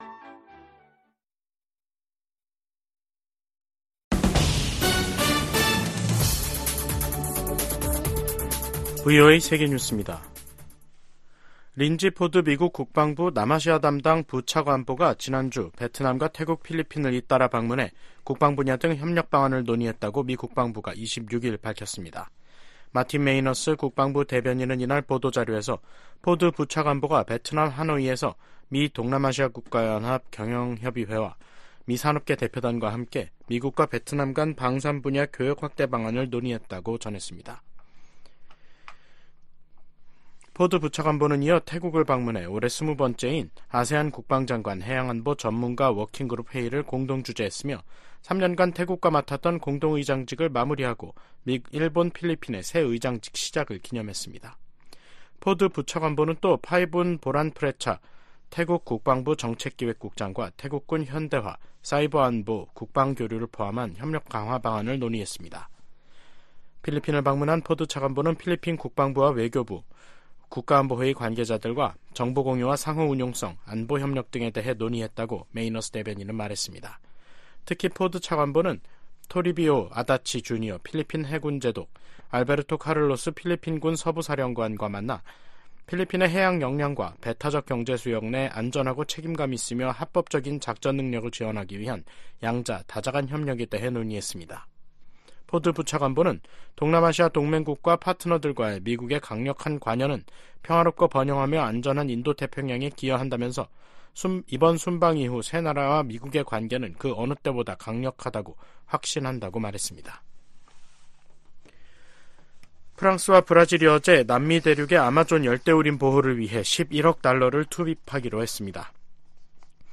VOA 한국어 간판 뉴스 프로그램 '뉴스 투데이', 2024년 3월 27일 3부 방송입니다. 북한의 김여정 노동당 부부장이 일본과의 정상회담과 관련, 어떤 교섭이나 접촉도 거부할 것이라고 밝혔습니다. 미국 정부가 북한-러시아 군사 협력에 우려를 거듭 표명하고, 북한 무기가 무고한 우크라이나인 살해에 사용되고 있다고 지적했습니다. 기시다 후미오 일본 총리의 다음달 국빈 방미를 계기로 미일 군사 안보 동맹이 업그레이드 될 것으로 전문가들은 전망하고 있습니다.